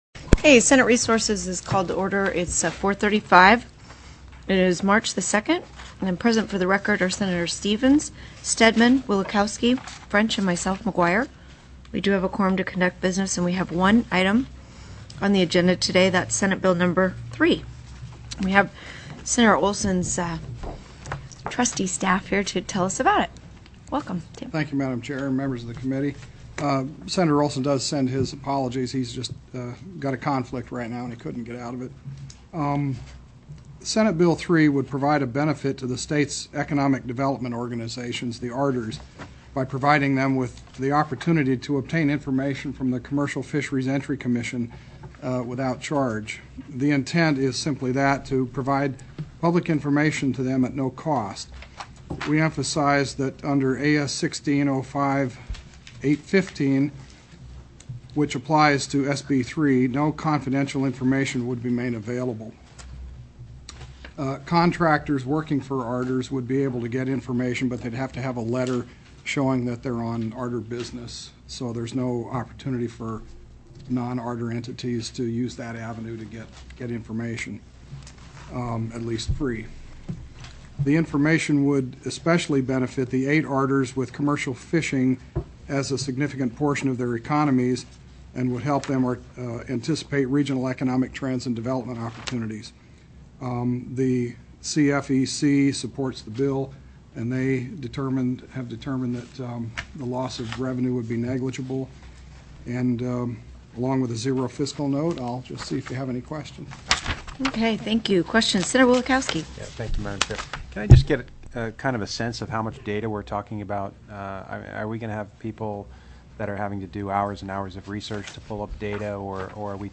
+ teleconferenced
SENATE RESOURCES STANDING COMMITTEE
CO-CHAIR MCGUIRE closed public testimony.